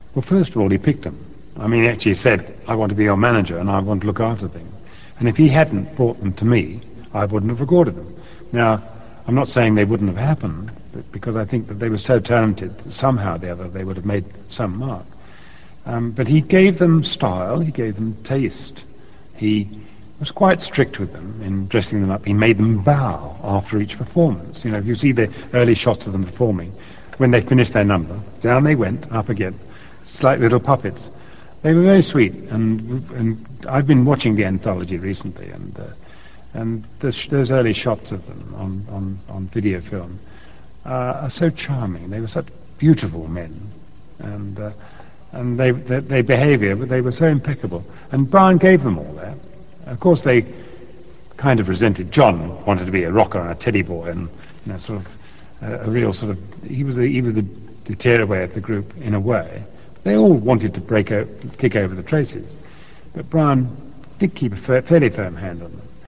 Here are a few never before seen extracts from those interviews: